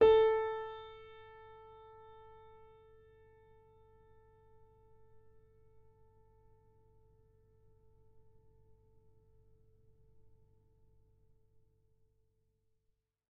sampler example using salamander grand piano
A4.ogg